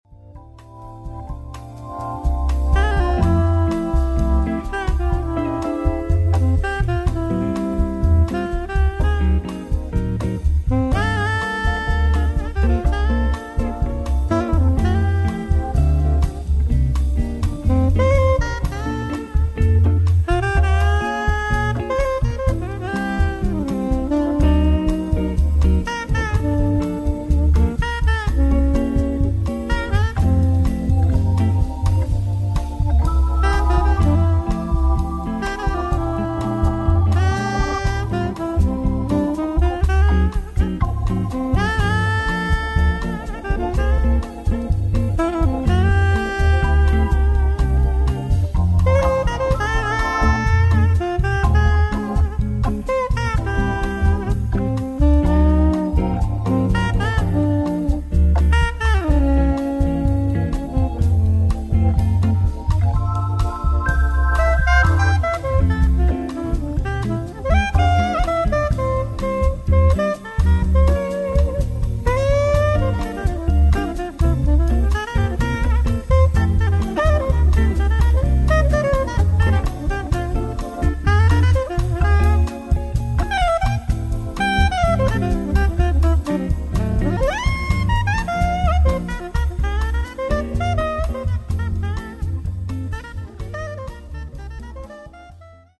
saxophonist
I play it in Wes-style octaves in ensemble, but also as an acoustic solo guitar arrangement.